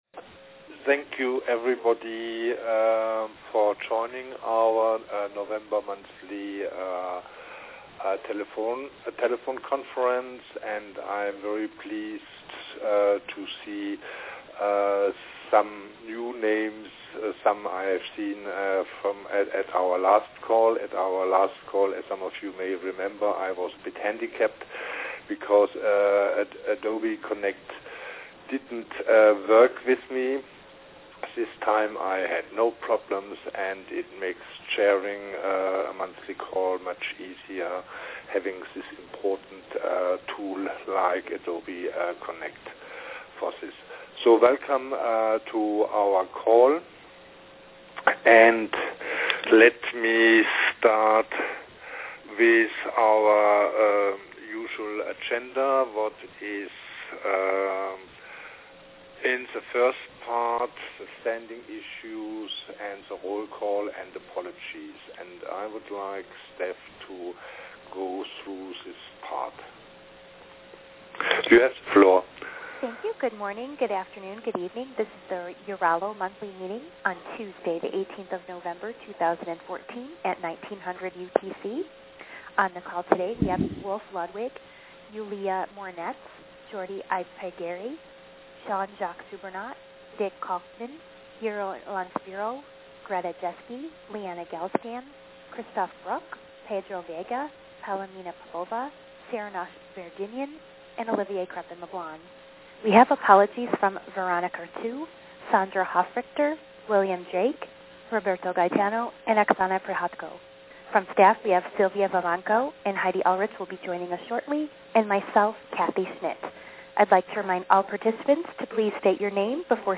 EURALO Monthly December Teleconference